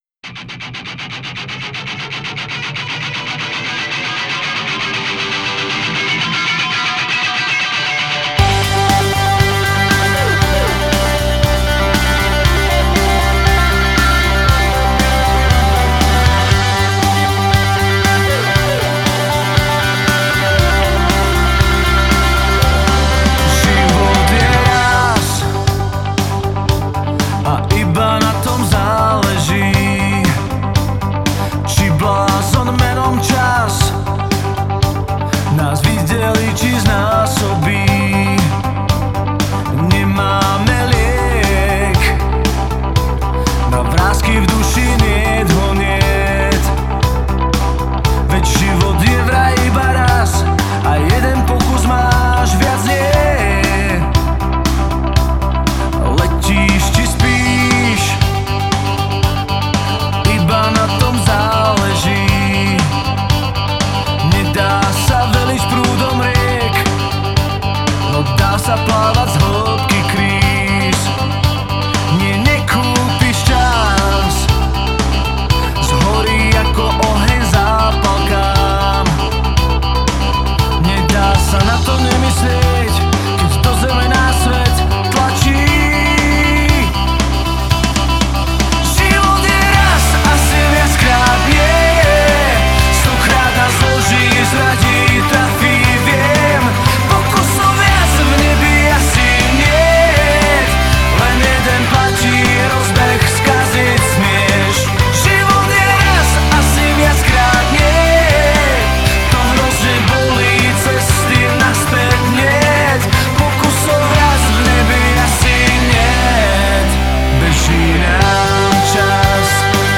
gitary
basgitary
klávesy
bicie